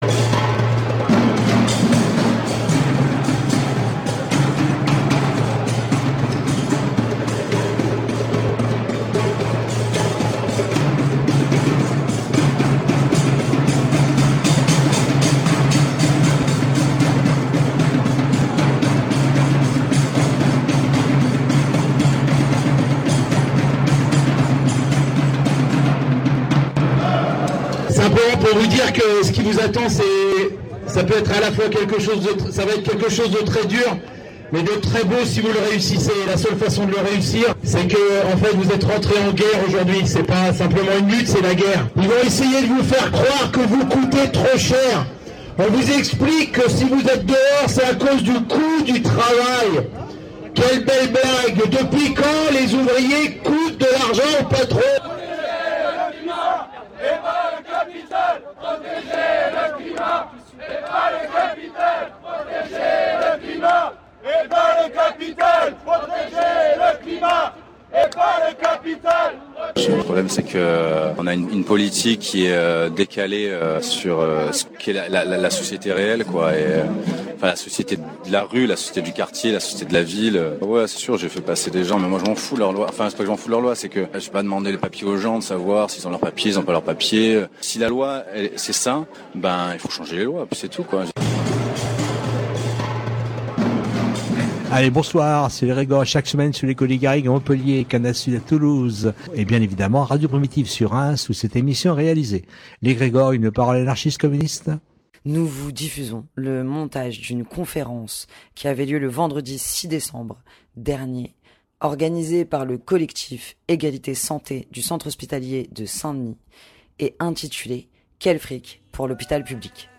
Le Vendredi 6 décembre avait lieu une conférence autour du financement de l’hôpital public, à la bourse du travail de Saint Denis, organisée par le collectif “Egalité santé”.